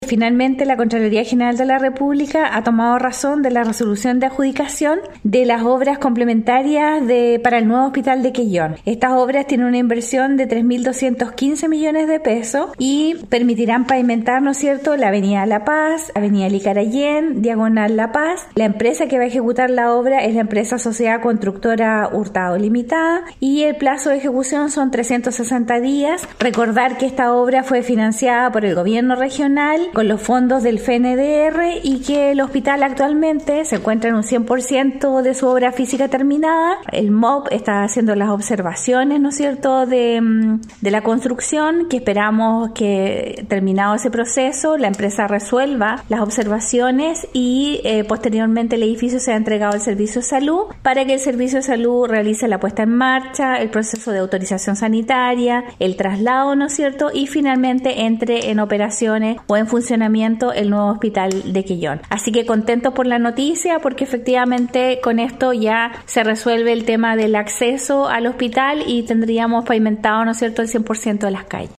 Al respecto, la funcionaria señaló: